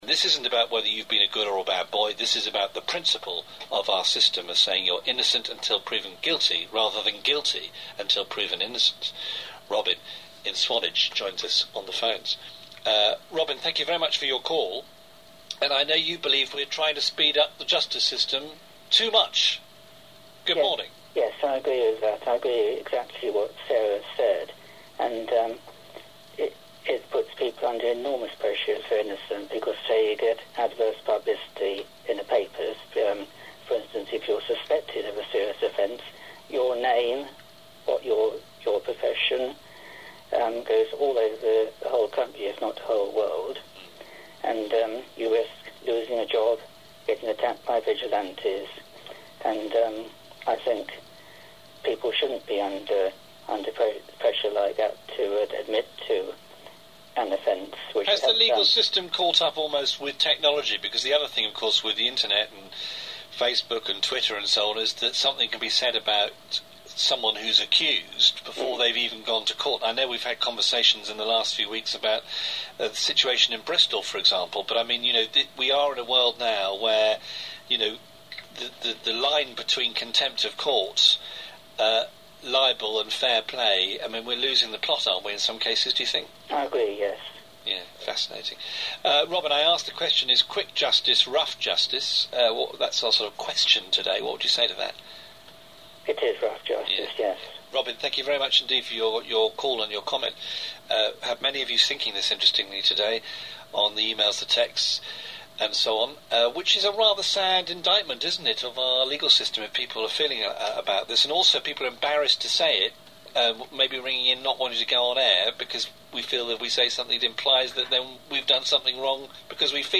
Here are extracts from the Radio Solent program this morning, relating to Yet more staggering incompetence at Dorset Road Safe
Discussion
Caller 1
Caller 2